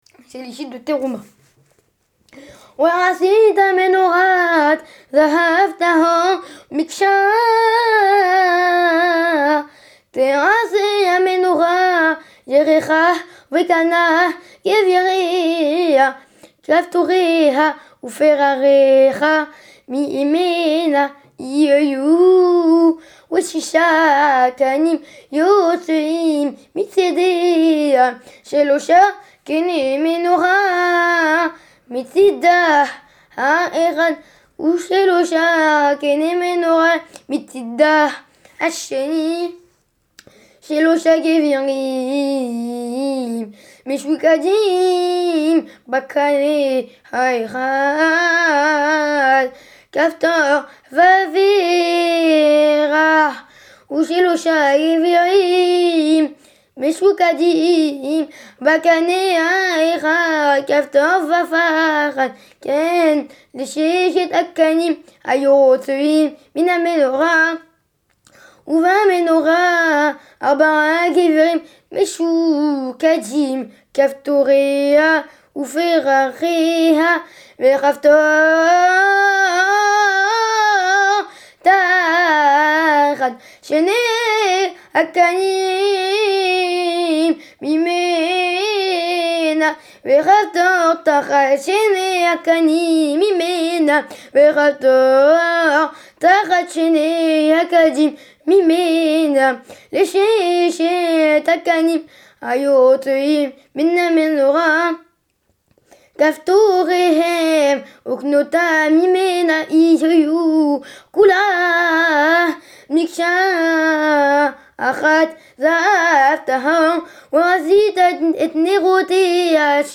Notes : Pérék 25, Passouk 33 : Nous lisons Machoukadimes et pas Méchoukadimes